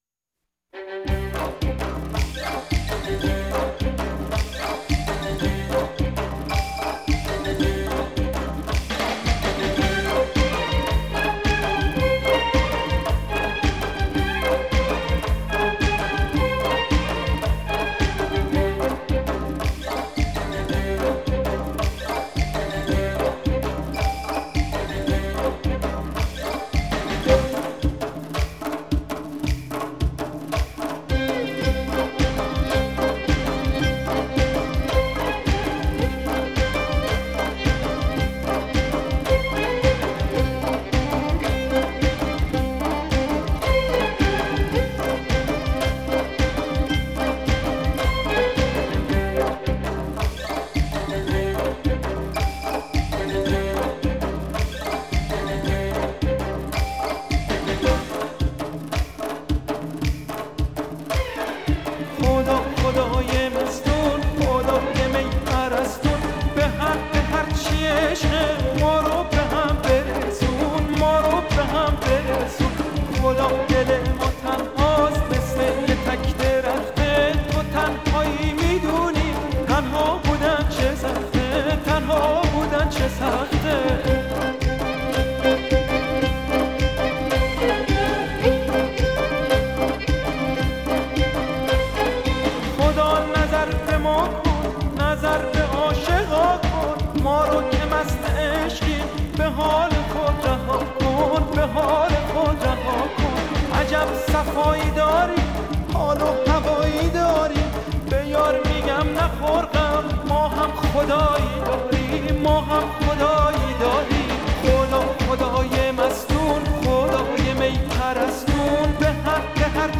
ترکیبی از موسیقی سنتی ایرانی و سازهای مدرن
ملودی‌های احساسی و ریتم‌های پرانرژی